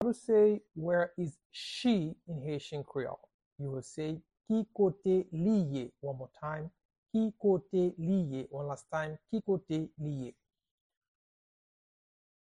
Pronunciation and Transcript:
How-to-say-Where-is-she-in-Haitian-Creole-–-Ki-kote-li-ye-pronunciation-by-a-Haitian-teacher.mp3